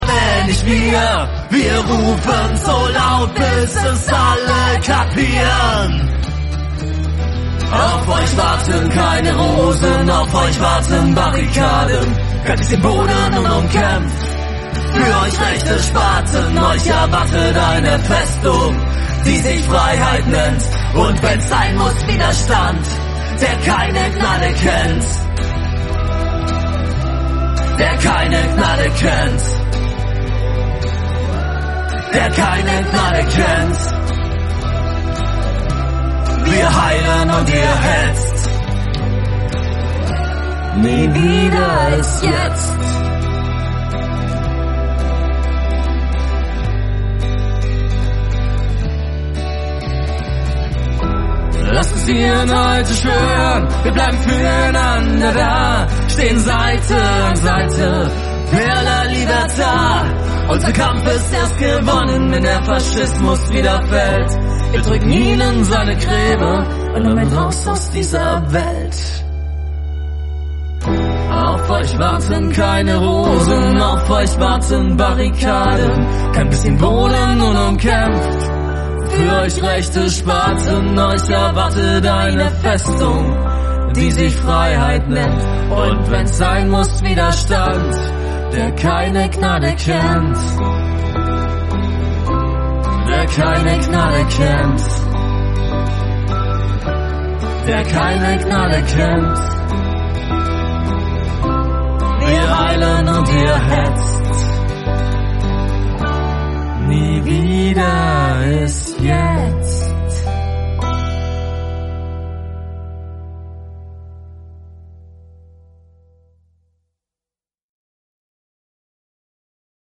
Ungewöhnlich ruhige Töne mit hoher Sprengkraft
Punkrockband